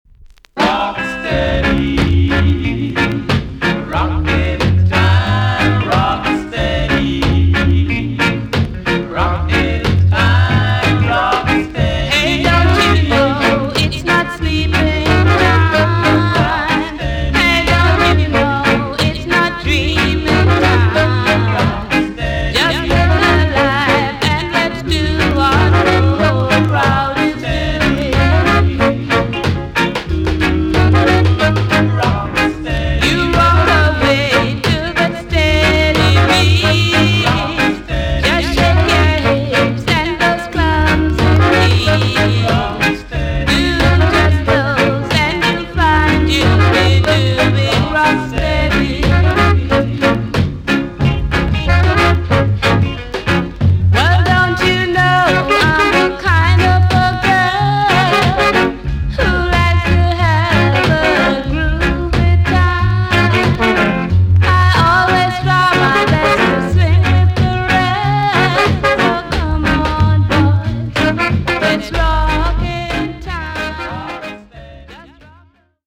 EX-~VG+ 少し軽いチリノイズが入りますがキレイです。